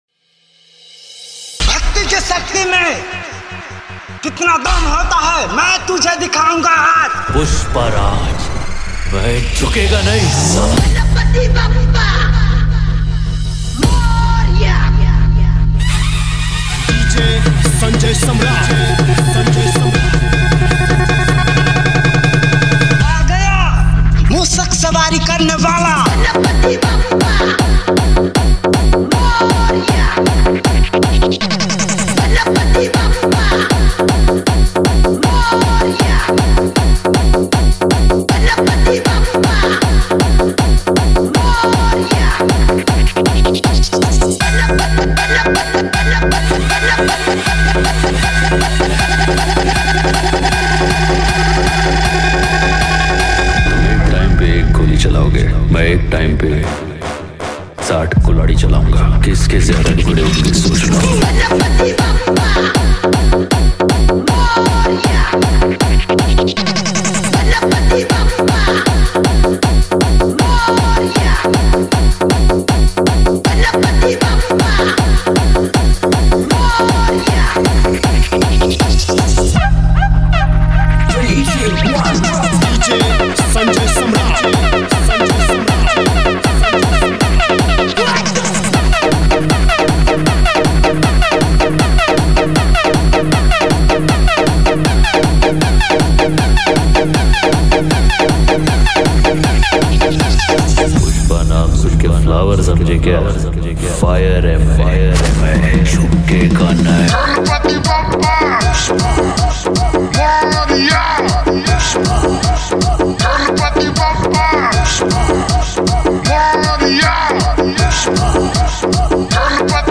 Ganesh Puja Special Dj 2023